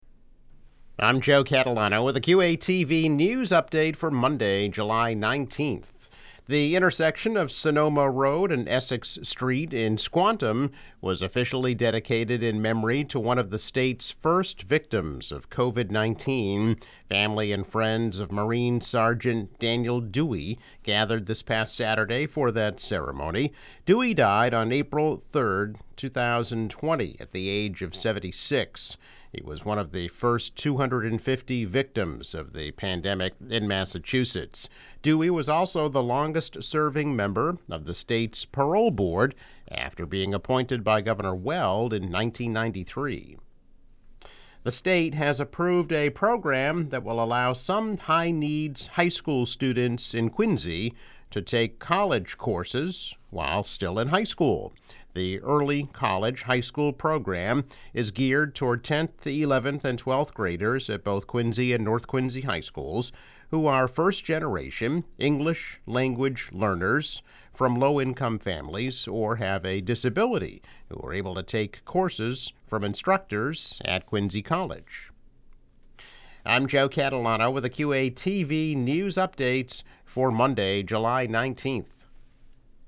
News Update - July 19, 2021